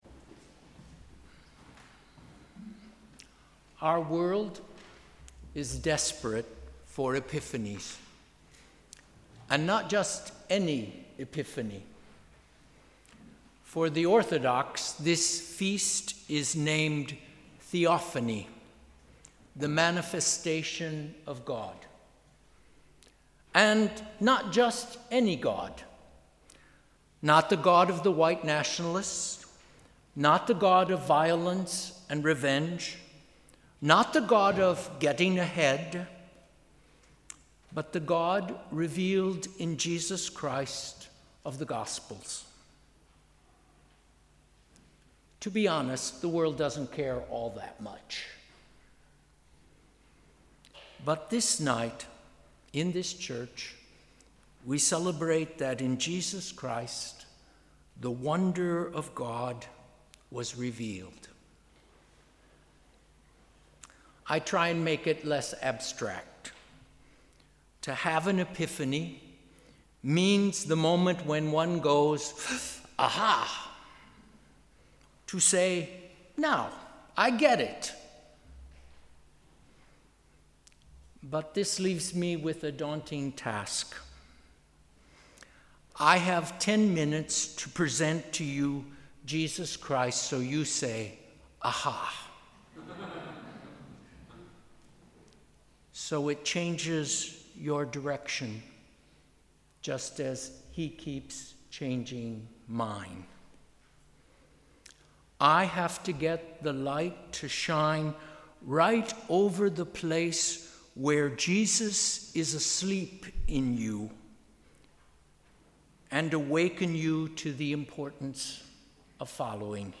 Sermon: ‘Desperate for epiphanies’